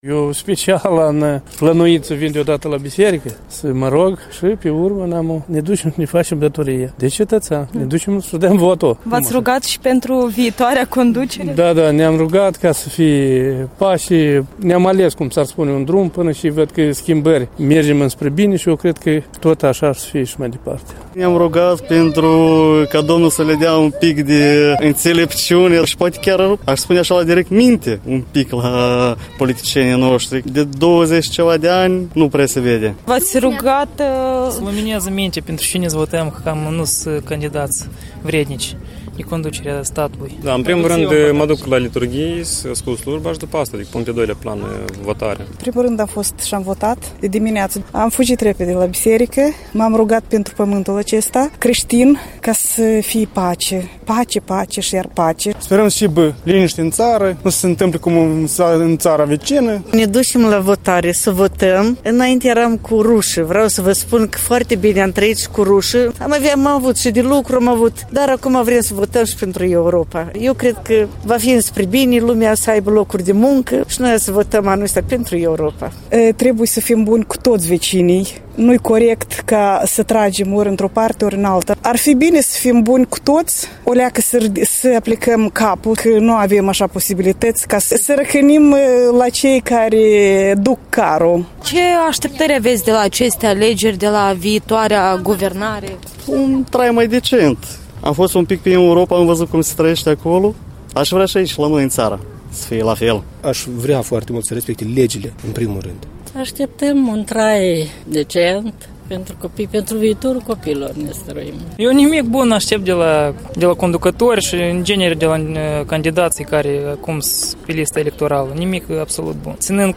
Alegători moldoveni intervievați în fața catedralei Mitropoliei Chișinăului și Moldovei, în dimineața alegerilor.